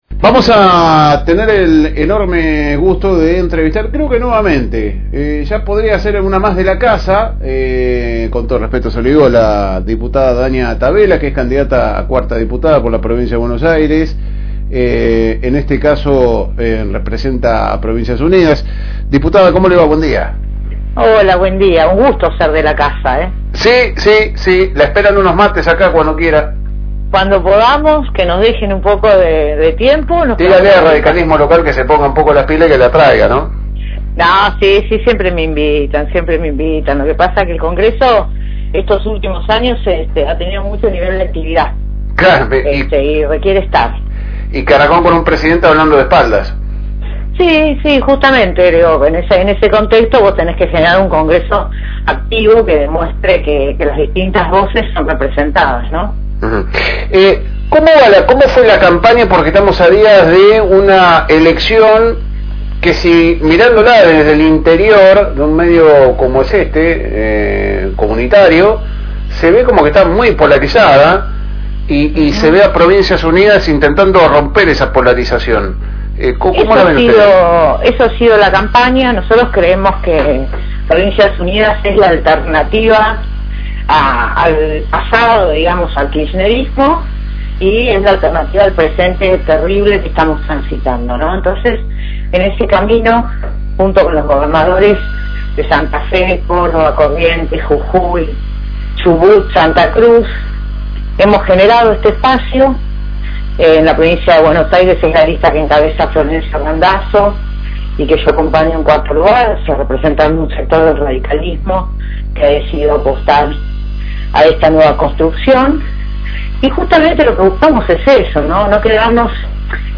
En este caso charlamos con la candidata a diputada por la lista de Alianza Provincias Unidas Danya Tavela, que nos contaba un poco como viene la recta final de la campaña y cuales son las cosas que va a discutir en caso de ser electa.